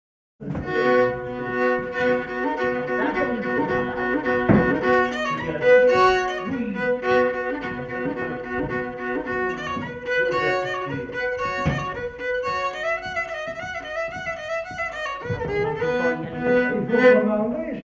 Quadrille
Pièce musicale inédite